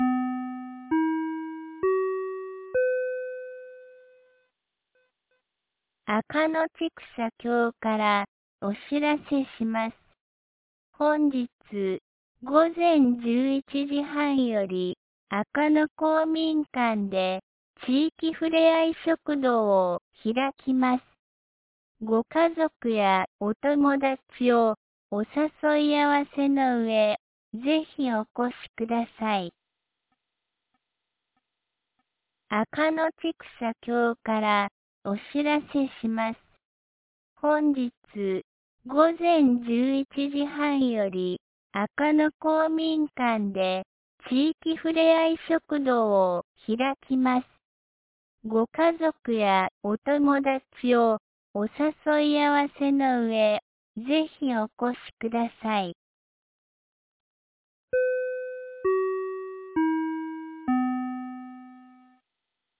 2025年11月09日 09時01分に、安芸市より赤野へ放送がありました。